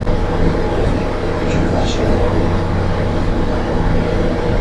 It was captured on a digital voice recorder that we had placed on the bed upstairs in the master bedroom.